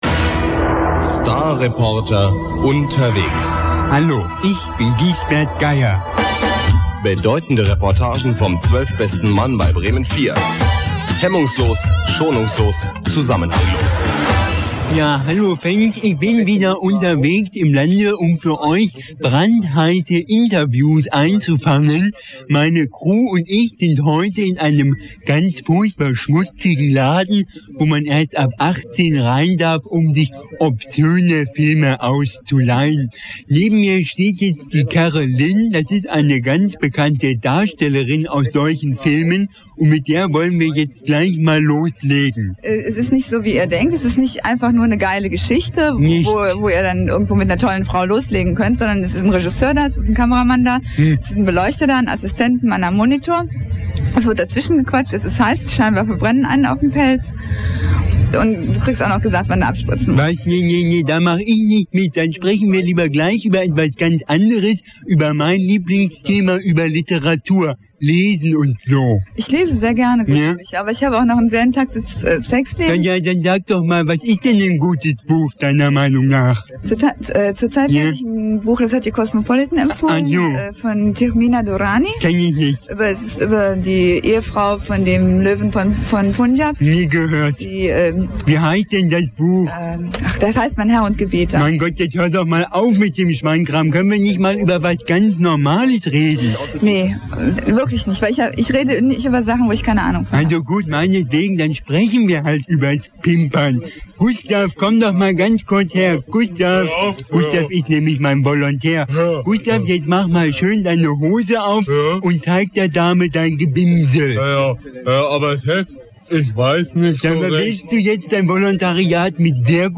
Die allererste Starreportage mit Gisbert Geier von 1994, die damals aber nicht gesendet wurde.